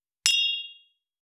266,アルコール,バー,お洒落,モダン,カクテルグラス,ショットグラス,おちょこ,テキーラ,シャンパングラス,カチン,チン,カン,
コップ